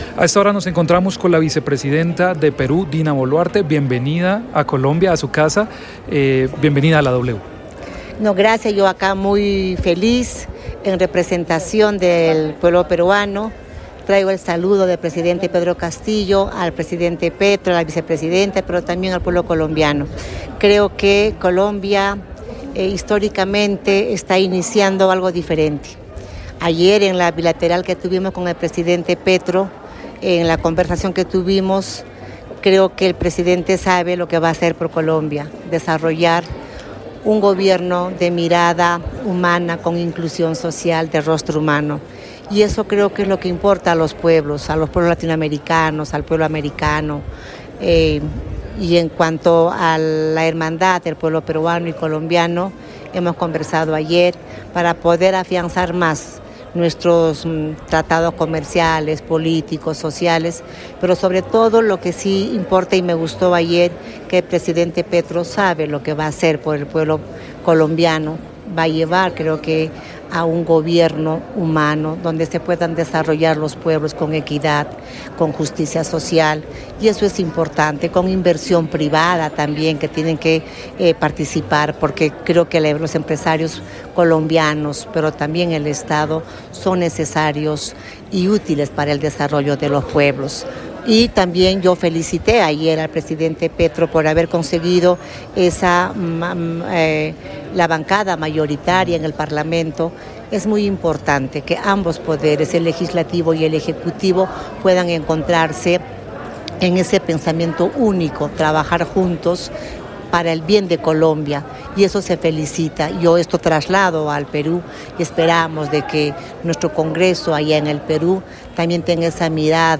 En el encabezado escuche la entrevista completa con Dina Boluarte, vicepresidenta de Perú, quien estuvo en la posesión de Gustavo Petro.